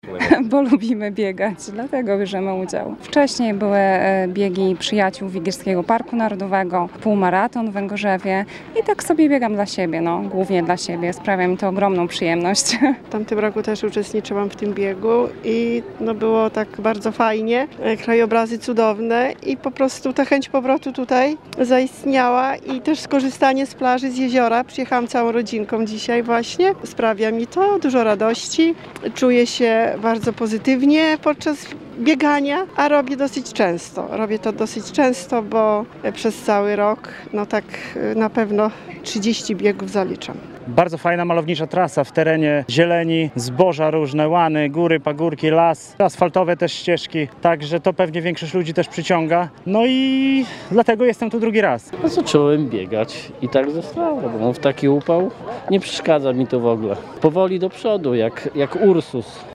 – Biegamy głównie dla siebie, sprawia nam to ogromną przyjemność – zapewniali uczestnicy.
uczestnicy-1.mp3